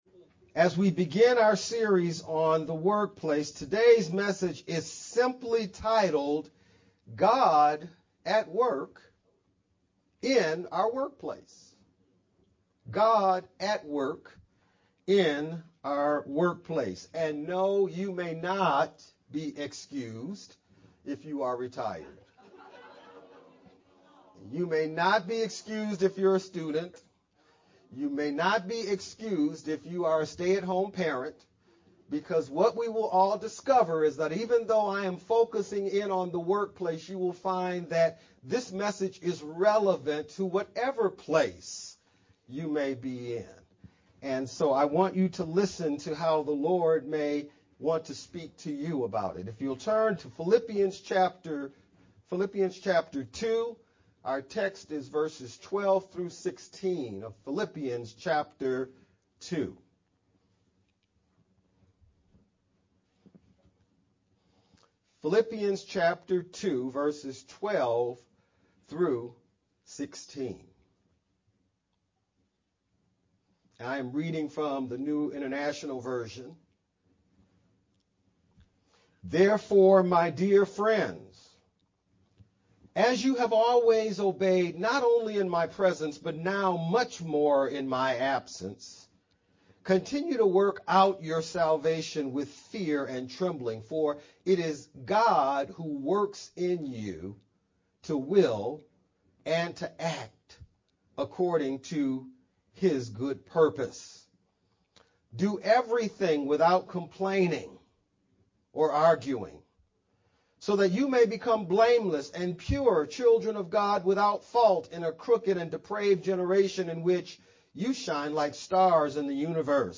May-7th-VBCC-Sermon-only-Mp3-CD.mp3